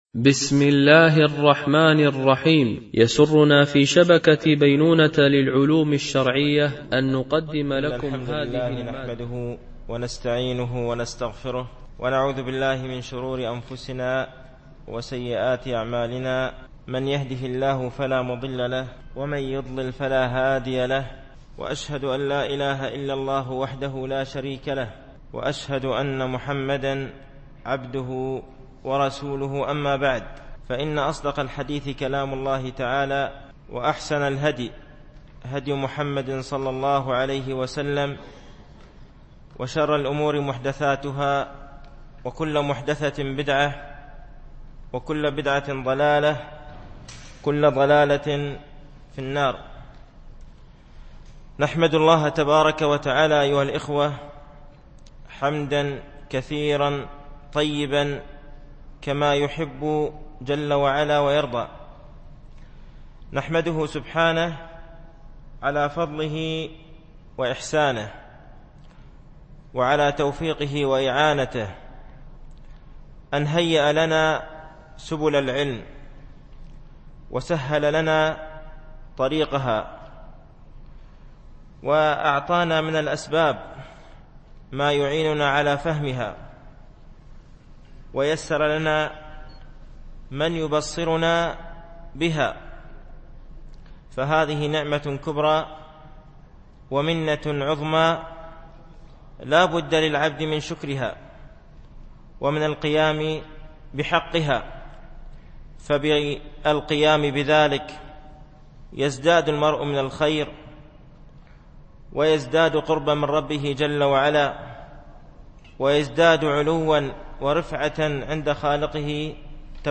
رسالة ابن القيم إلى أحد أخوانه - الدرس الأول
MP3 Mono 22kHz 32Kbps (CBR)